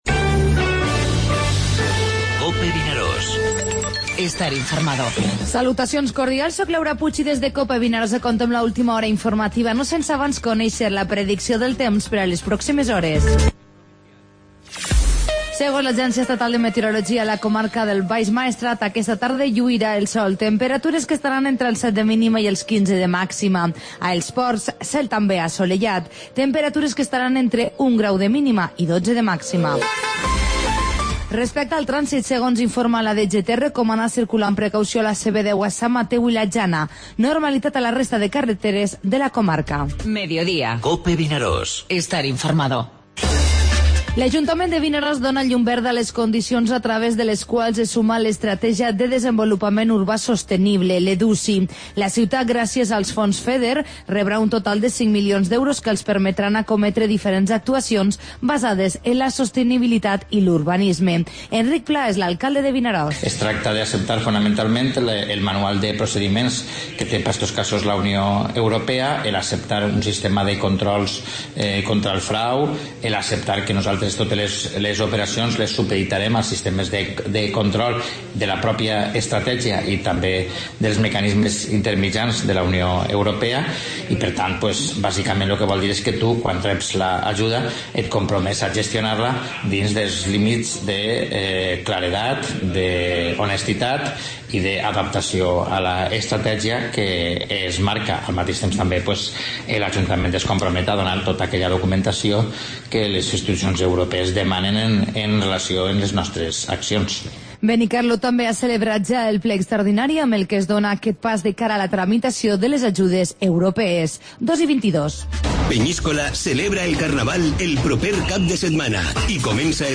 Informativo Mediodía COPE al Maestrat (dimarts 14 de febrer)